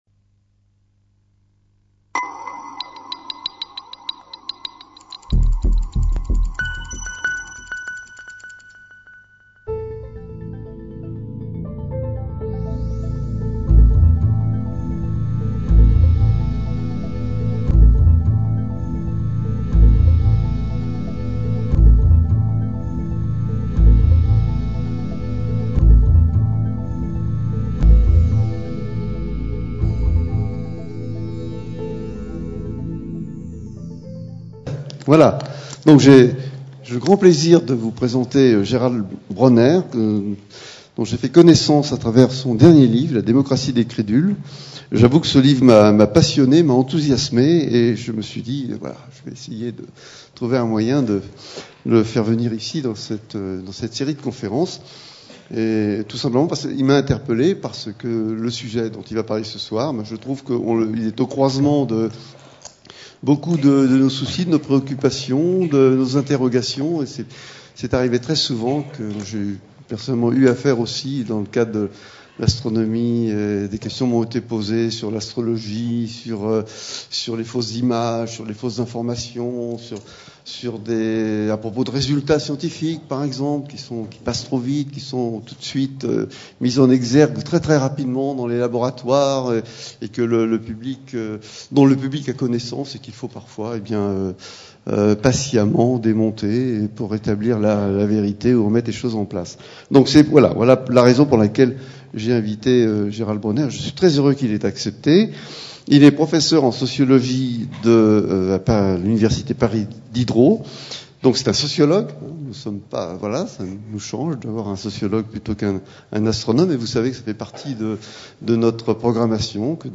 Conférence de l'Institut d'Astrophysique de Paris présentée par Gérald Bronner, professeur de Sociologie Université Paris-Diderot (Paris 7) le 1er avril 2014. Pourquoi les mythes du complot envahissent-ils l'esprit de nos contemporains ? Pourquoi se méfie-t-on toujours des hommes de sciences ?